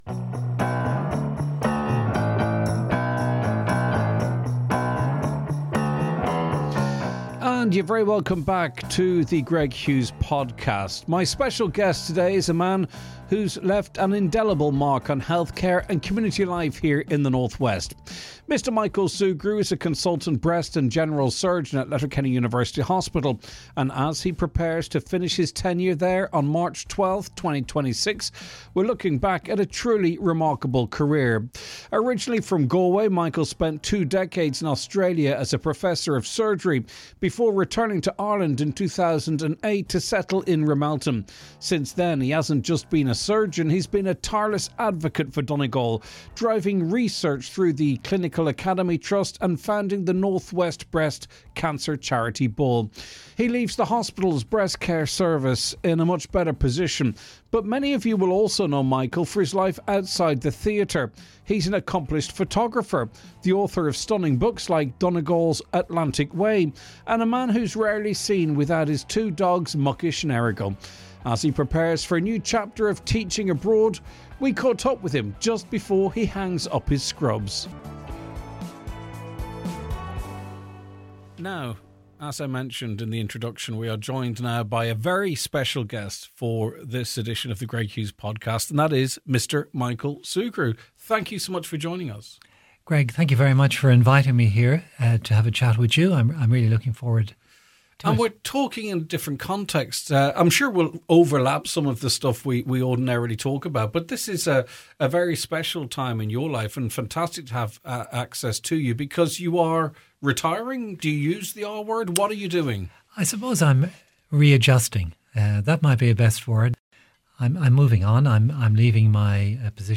Join us for a wide-ranging conversation with a true gentleman of the North West.